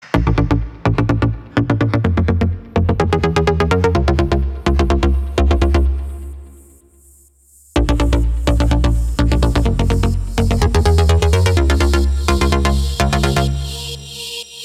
• Качество: 320, Stereo
ритмичные
громкие
Electronic
без слов
Tech House
Стиль: tech house